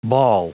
Prononciation du mot bawl en anglais (fichier audio)
Prononciation du mot : bawl